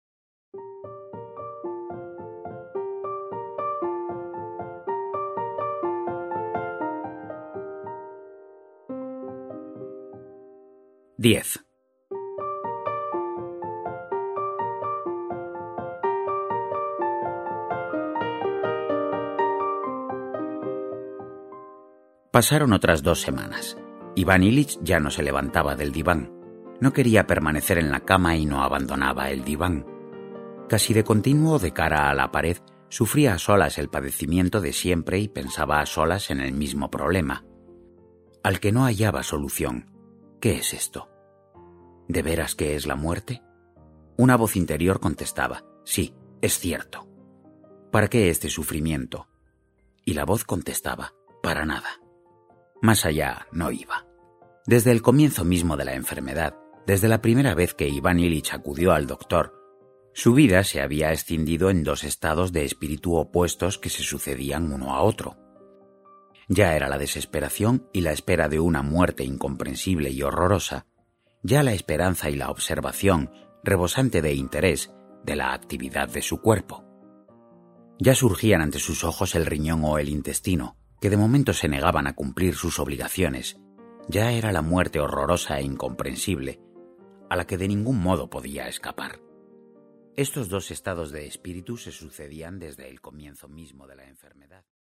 Escuchar Demo: